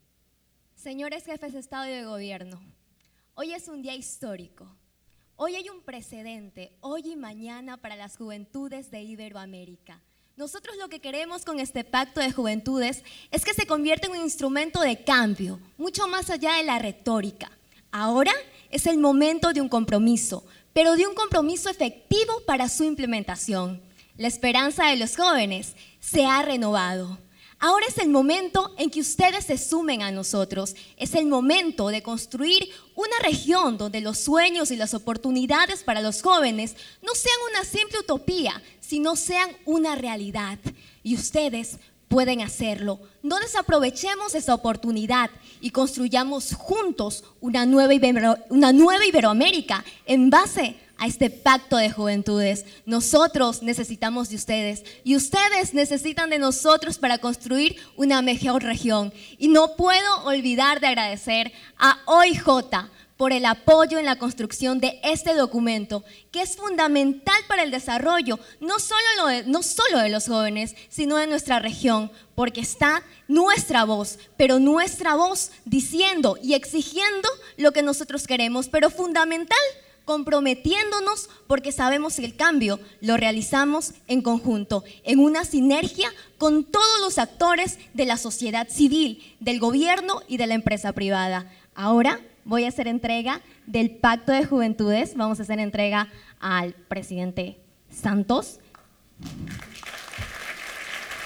durante la firma del Pacto Iberoamericano de Juventud